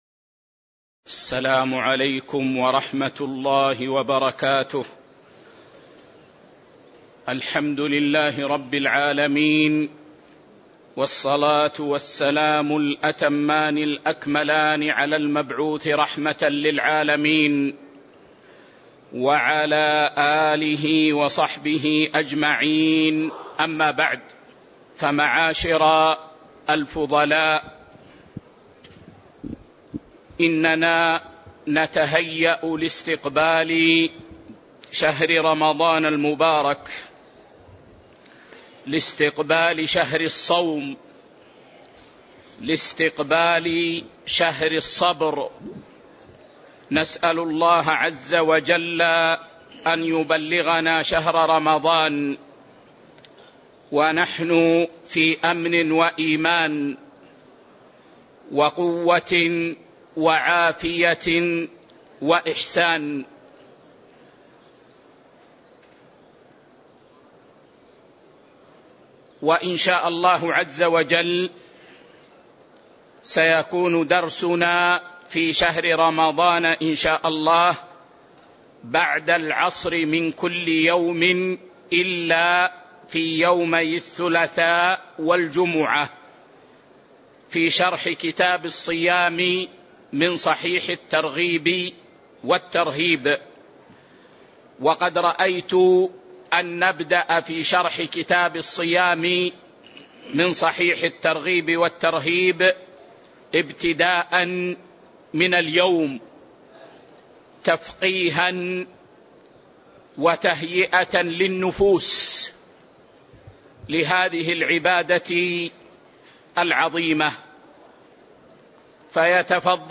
شرح صحيح الترغيب والترهيب 1 الدرس 85 كتاب الصيام 1 باب الترغيب في الصوم مطلقًا، وماجاء في فضله